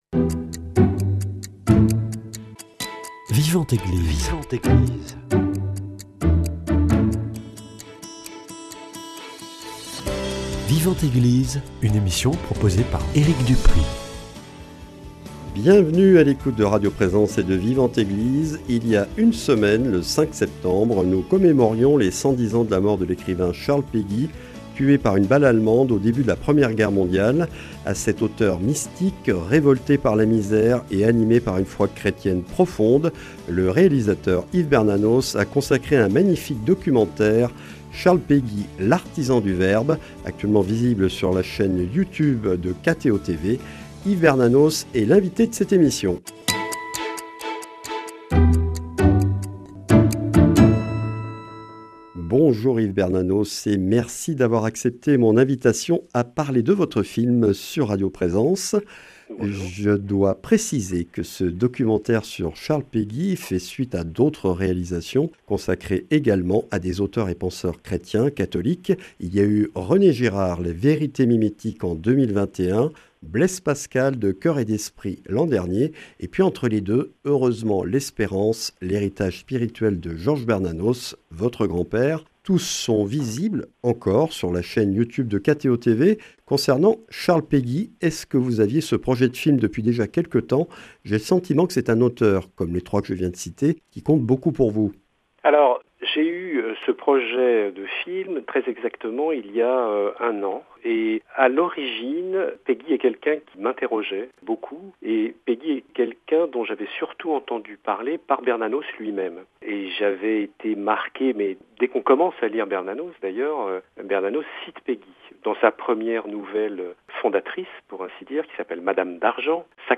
Entretien avec un artisan de l’image, réalisateur de films où souffle l’esprit des grands auteurs catholiques.